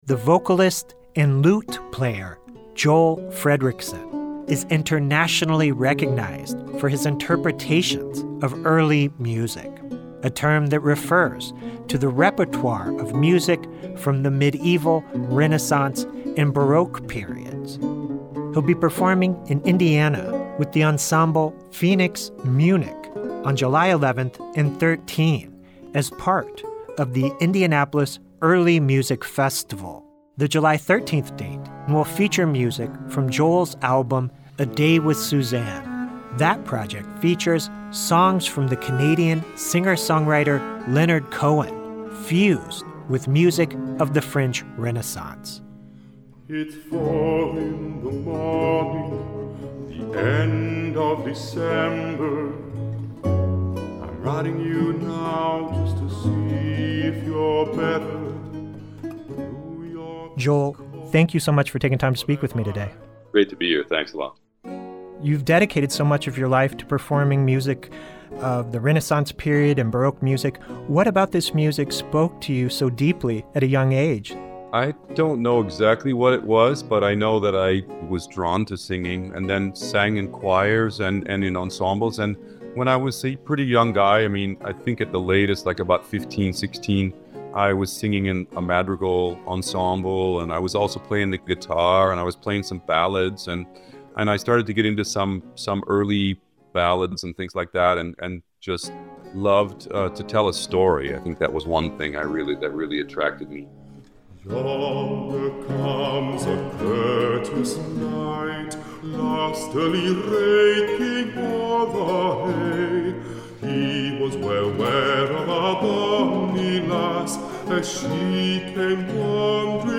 2025 Interview